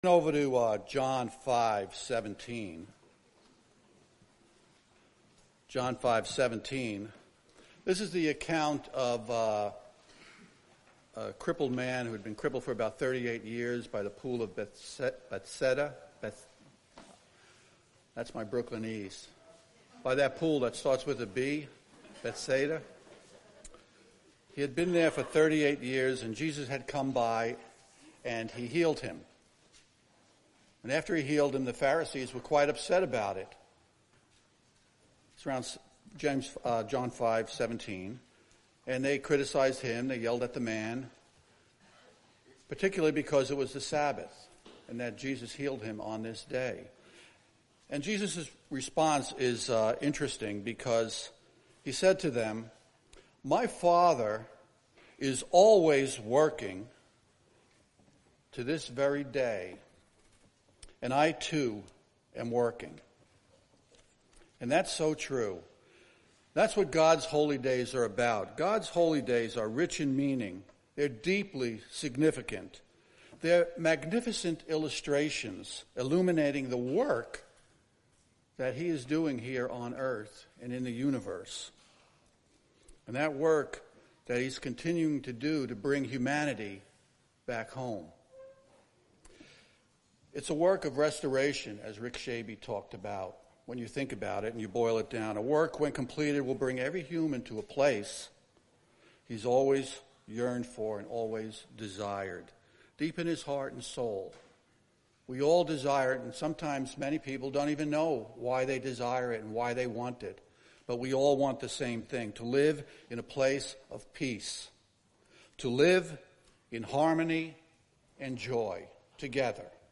Sermons
Given in North Canton, OH Sugarcreek, OH